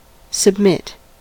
submit: Wikimedia Commons US English Pronunciations
En-us-submit.WAV